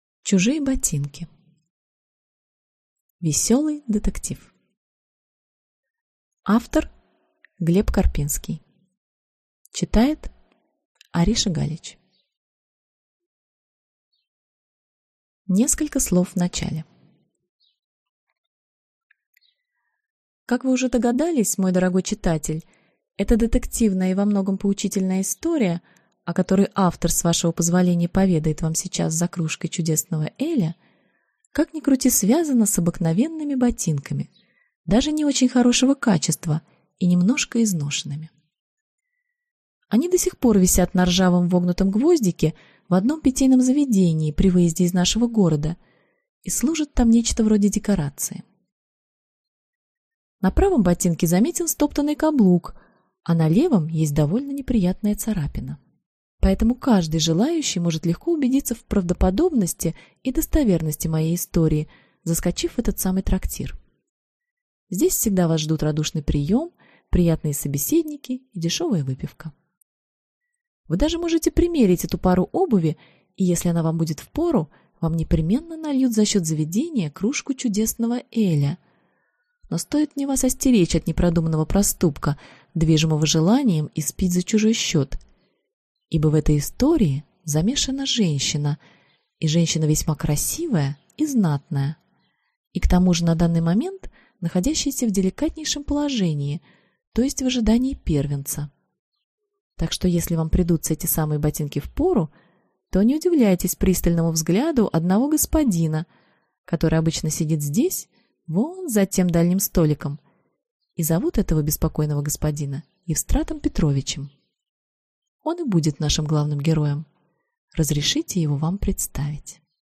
Аудиокнига Чужие ботинки. Веселый детектив | Библиотека аудиокниг